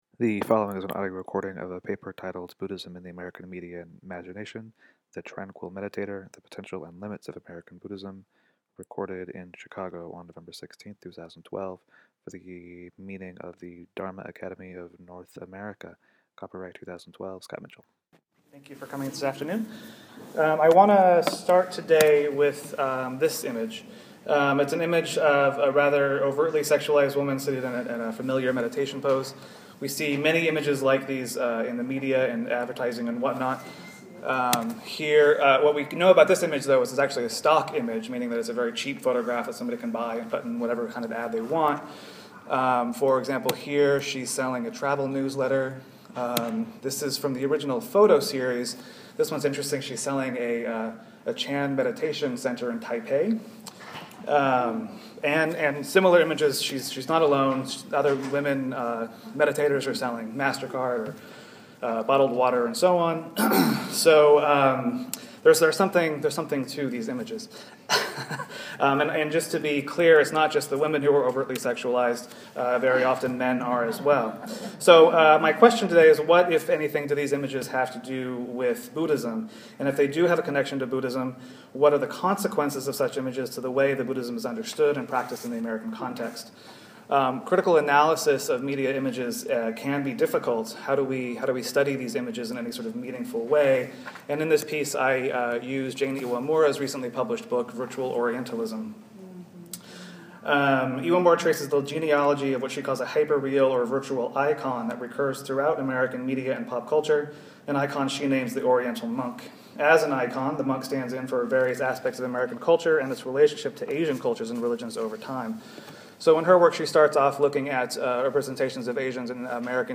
This past weekend at the American Academy of Religion, I participated in a panel for the Dharma Academy of North America.
The audio quality isn’t great, and I feel as though my argument is larger than a fifteen-minute presentation can really hold.